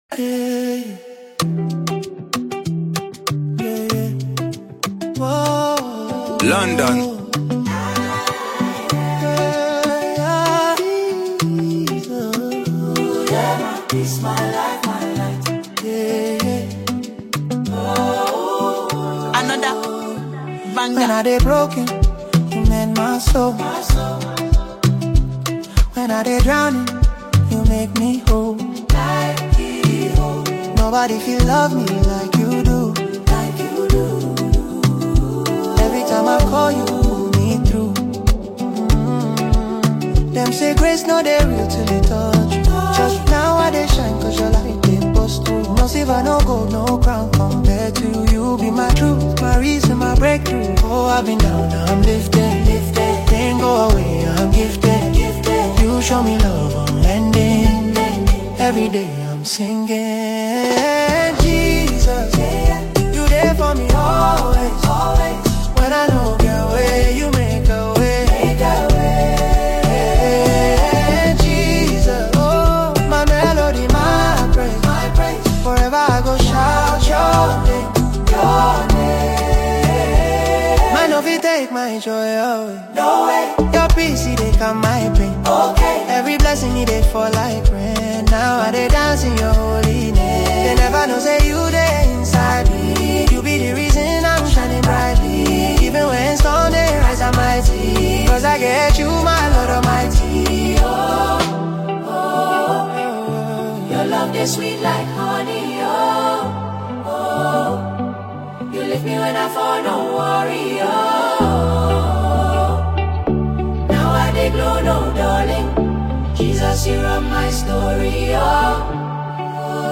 GOSPEL SONGSMusic